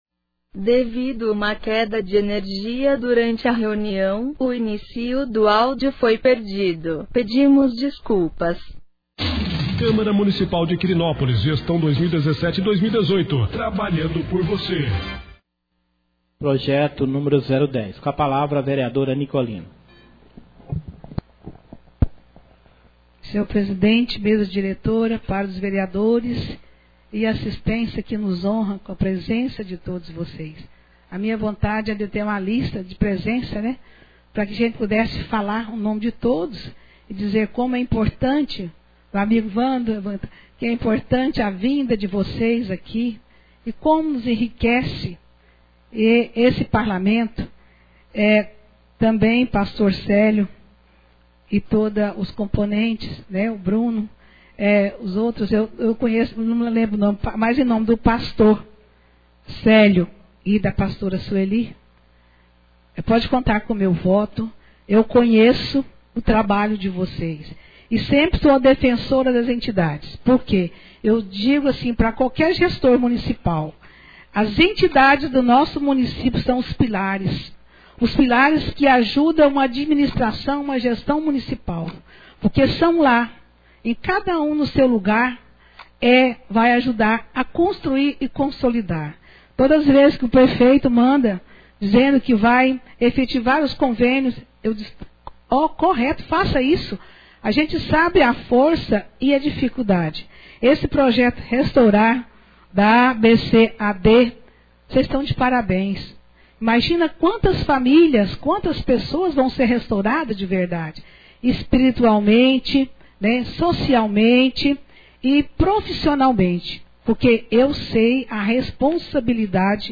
Devido uma queda de energia durante a reunião, o inicio do áudio foi perdido.